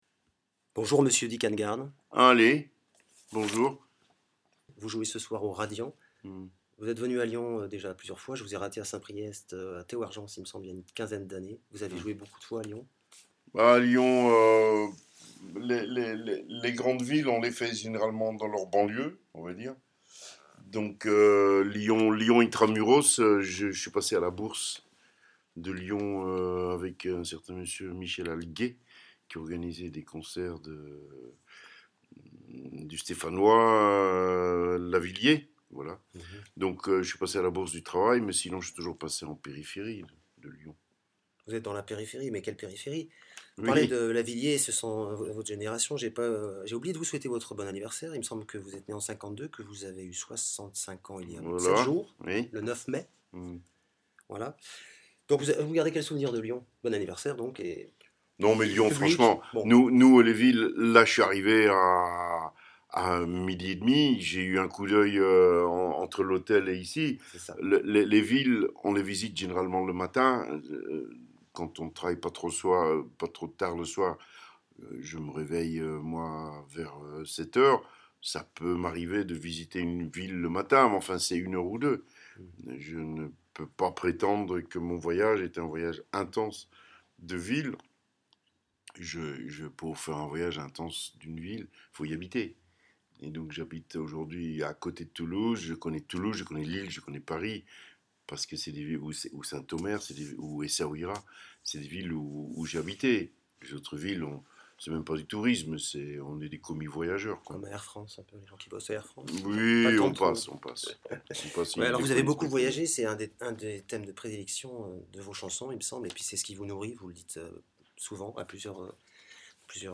Interview exclusive de Dick Annegarn le 17.5.17
Si ma voix tremblote un peu et trahit un léger trac, c’est que j’étais très impressionné de me retrouver en face d’un artiste de cette trempe admiré depuis plus de 30 ans et que je n’avais pas encore pu voir en concert.
Notre Chevalier des arts et des lettres m’a vite mis en confiance (après avoir lui-même résolu quelques problèmes techniques d’enregistrement !) et a répondu à quelques questions pendant une petite demi-heure.